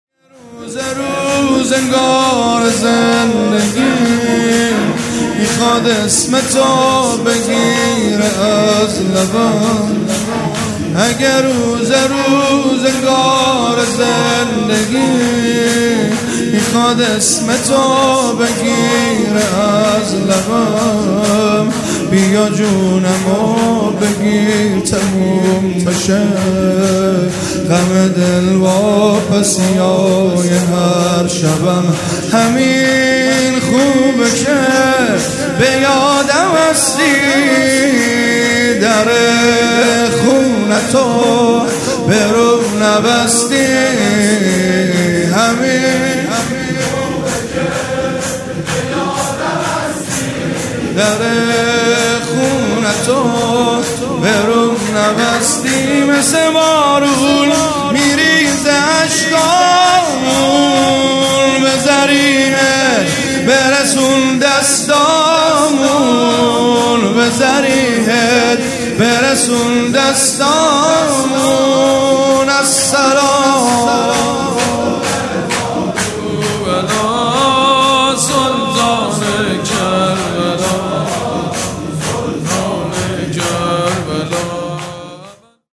مراسم مناجات شب بیست و چهارم ماه رمضان
شور
مداح